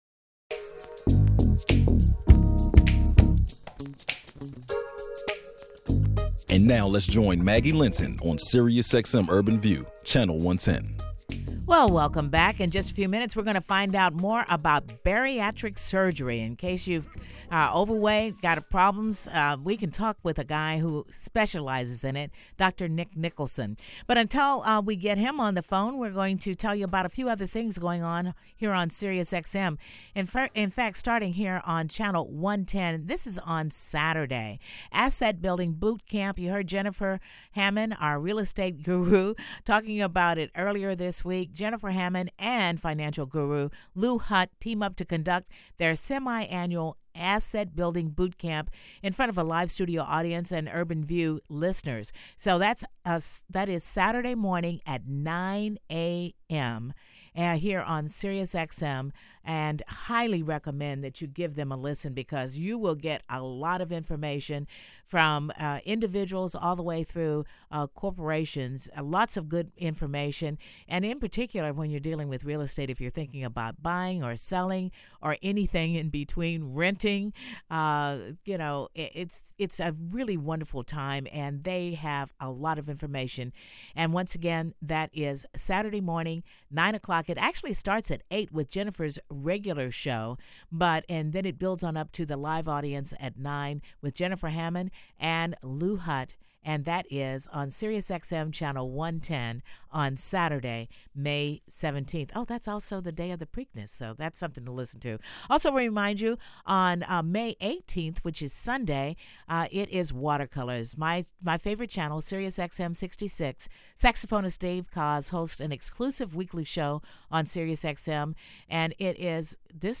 SiriusXM Urban View Channel 110, interviews